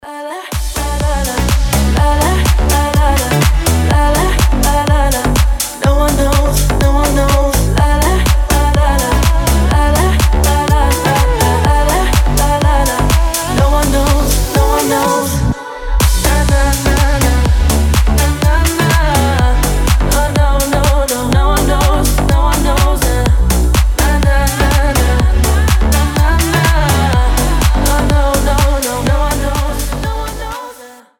• Качество: 320, Stereo
зажигательные
заводные
женский голос
EDM
future house
slap house
Что-то на грани future и slap house